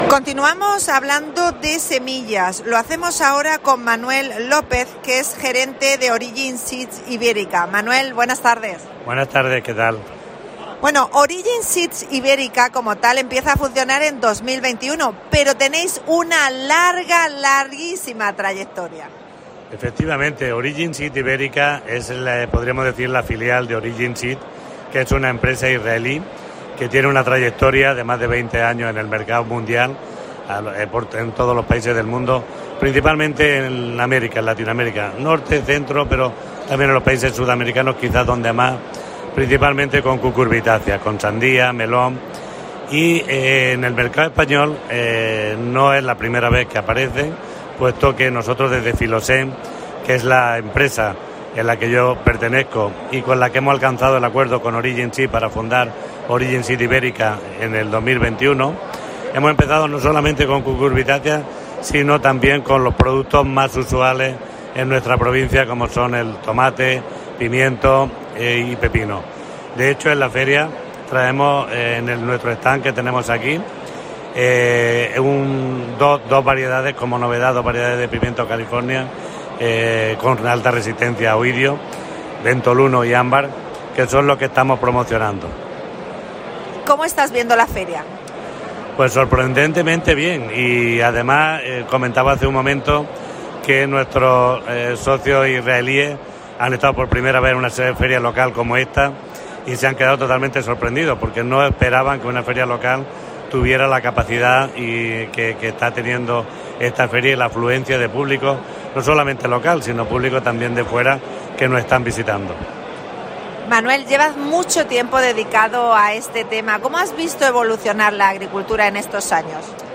AUDIO: Especial ExpoLevante. Entrevista